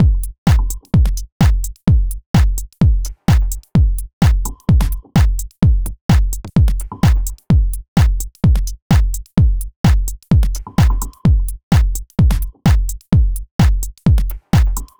• Rhythm Electro Drum Machine House Minimal - Fm - 128.wav
Rhythm_Electro_Drum_Machine_House_Minimal__-_Fm_-_128_D2h.wav